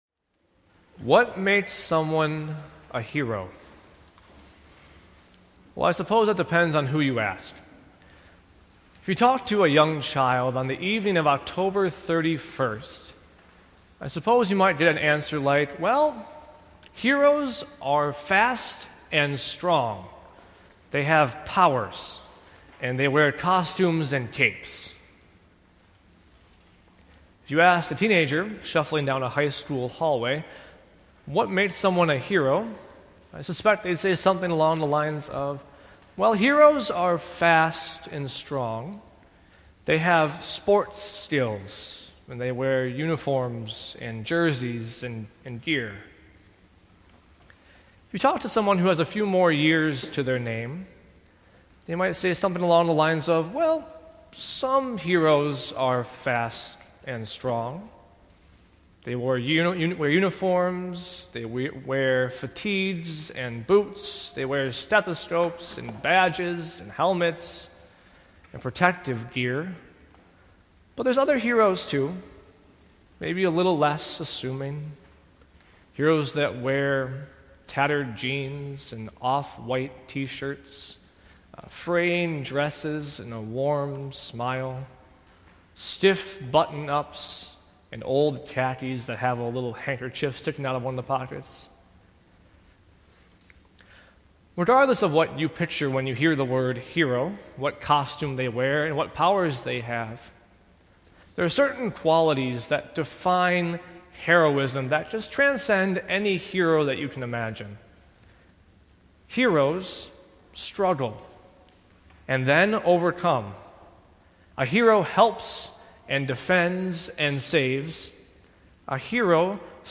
Sermons from Faith Evangelical Lutheran Church (WELS) in Antioch, IL